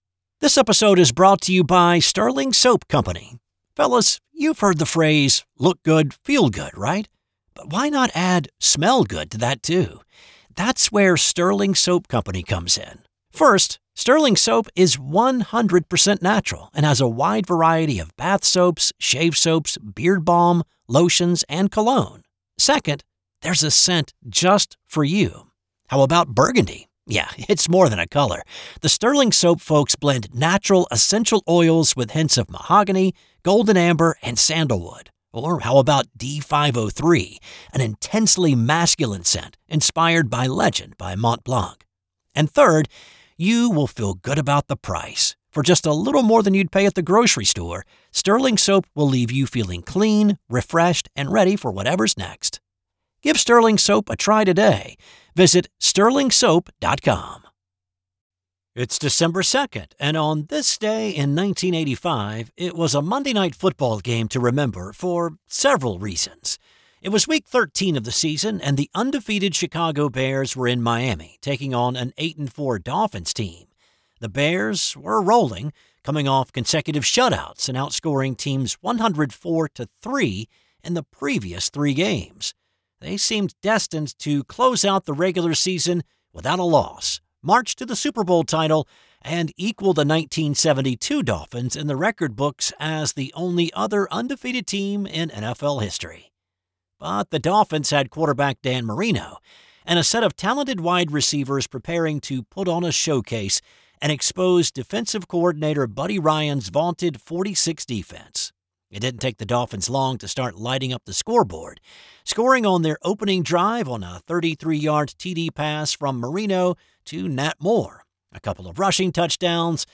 'This Day in Sports History' is a one person operation.